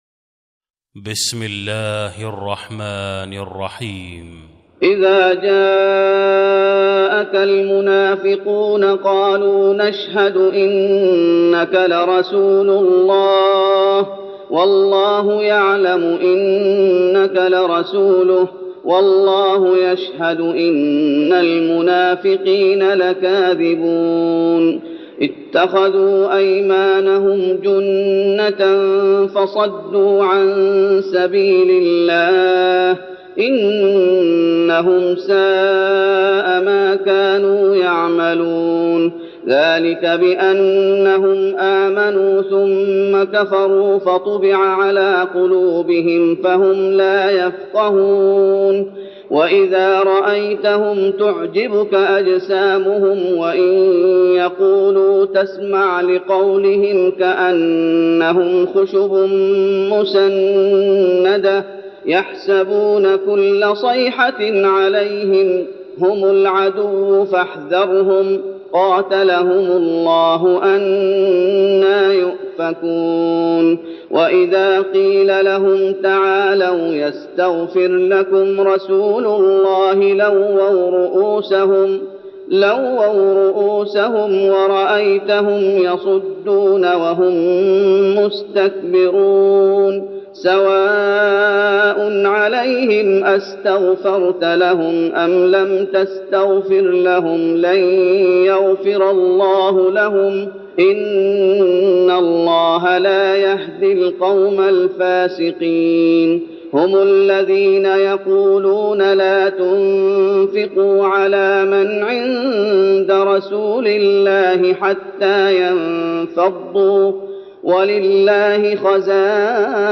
تراويح رمضان 1412هـ من سورة المنافقون Taraweeh Ramadan 1412H from Surah Al-Munaafiqoon > تراويح الشيخ محمد أيوب بالنبوي 1412 🕌 > التراويح - تلاوات الحرمين